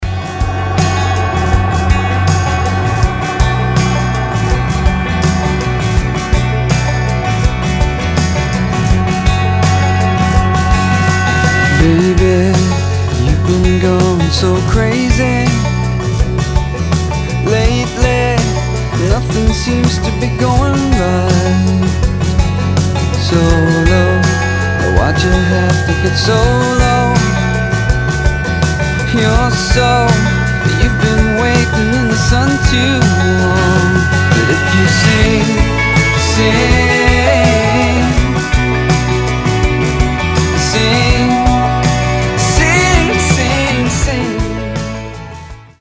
• Качество: 320, Stereo
красивые
лирика
романтика